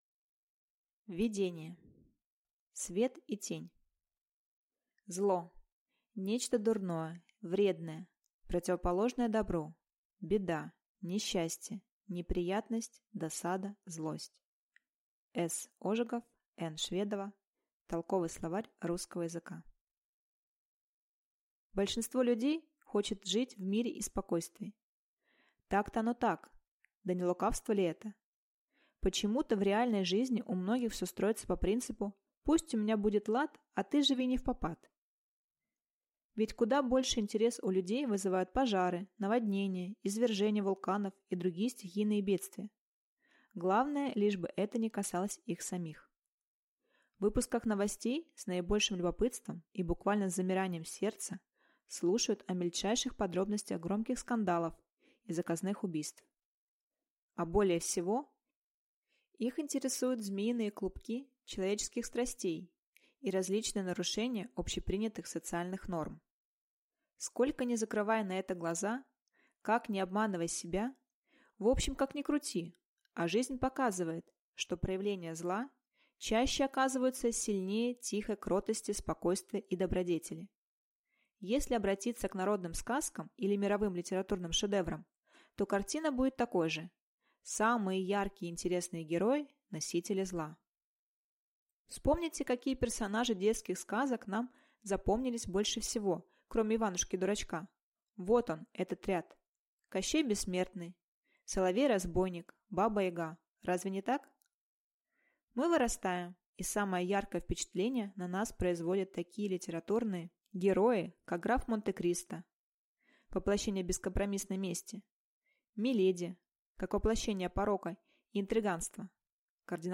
Аудиокнига Трансформация зла. Решение конфликтов внутренних и внешних | Библиотека аудиокниг
Прослушать и бесплатно скачать фрагмент аудиокниги